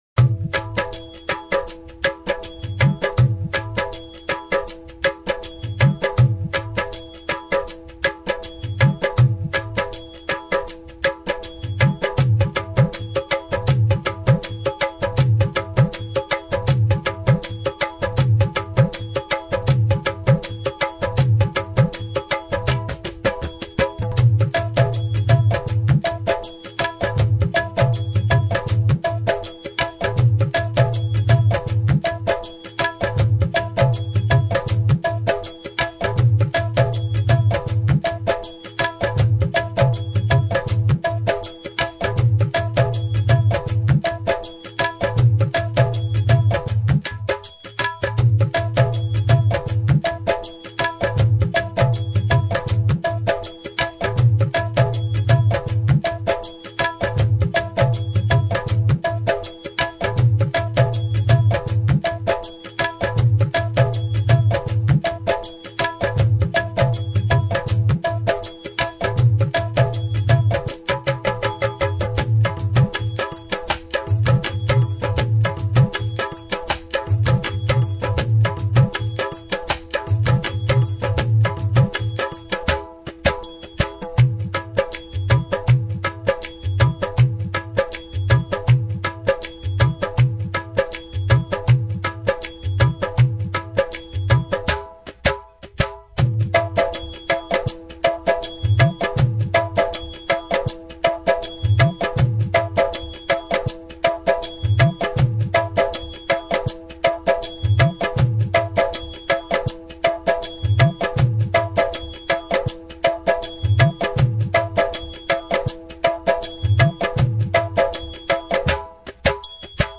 downloading dholak loop 1 please wait...........